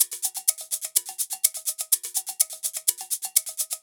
Live Percussion A 14.wav